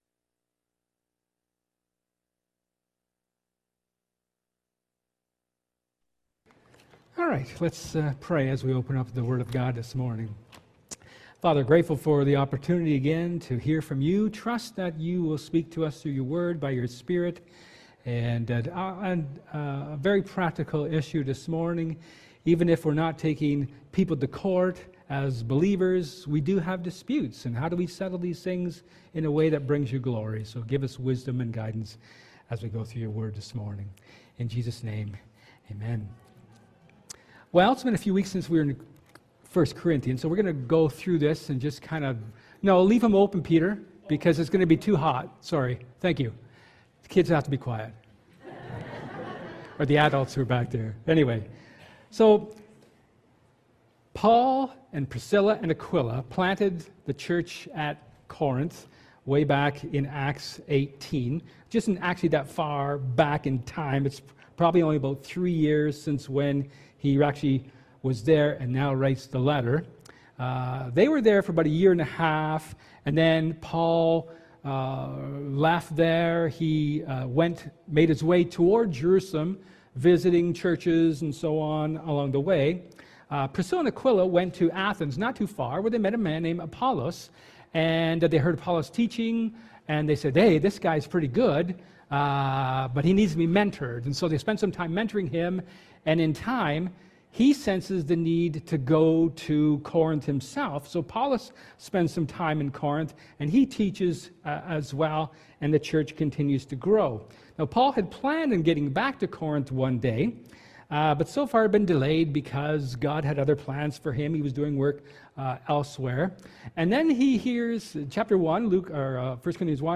1 Corinthians 7:10-24 Service Type: Sermon